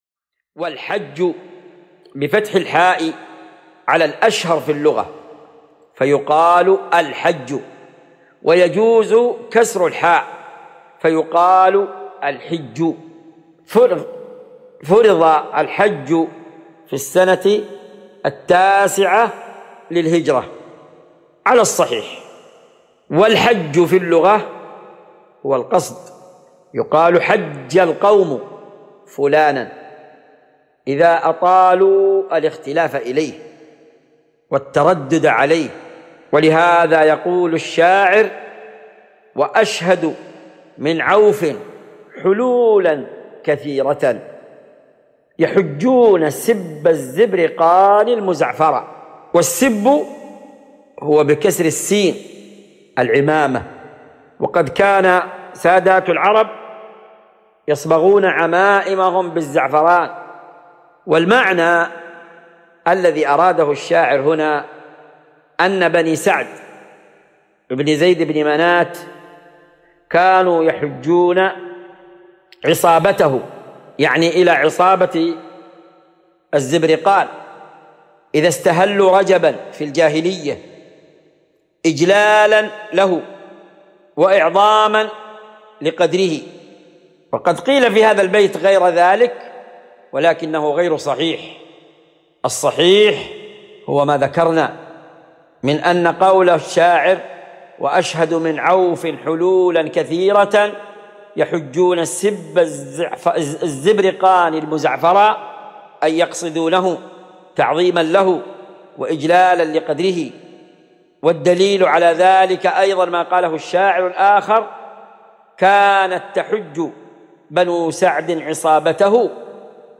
مقتطف من شرح كتاب الحج من عمدة الفقه الشريط الأول .